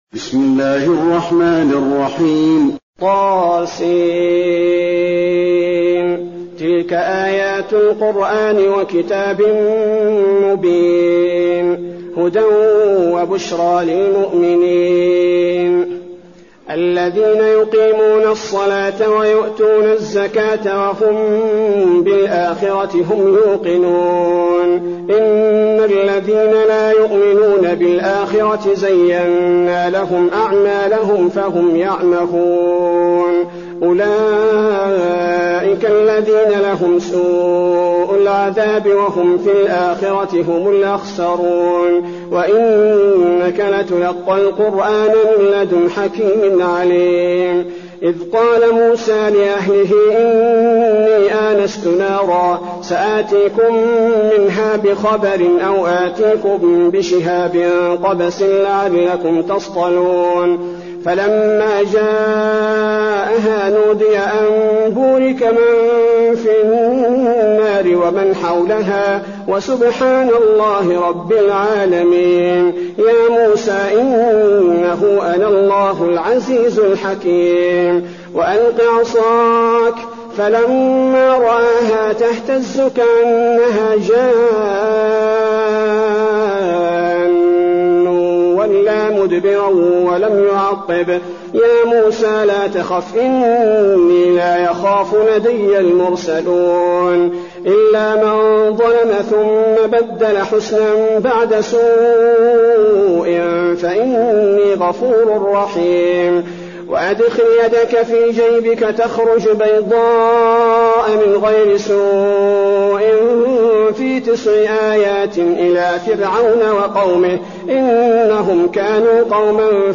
المكان: المسجد النبوي النمل